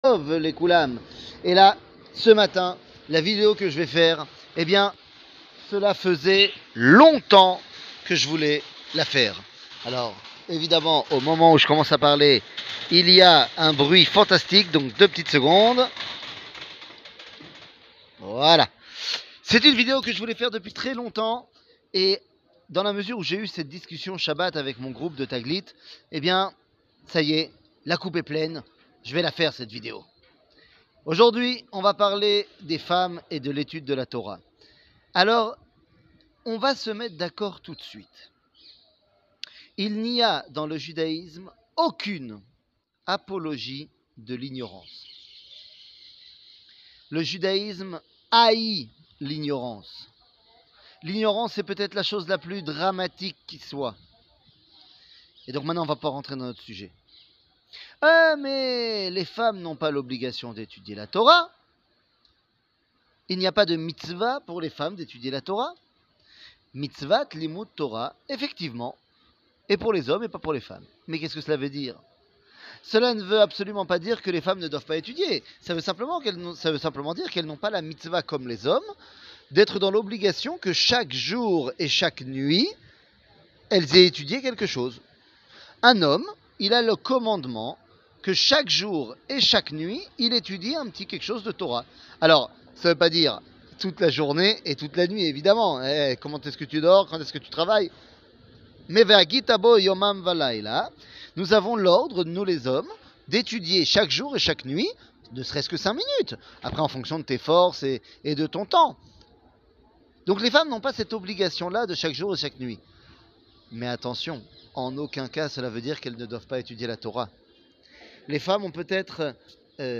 שיעור מ 24 יולי 2022
שיעורים קצרים